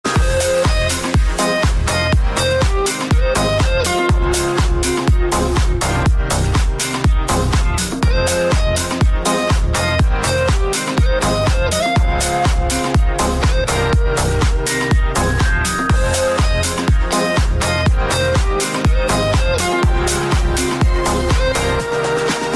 A chill song full of emotions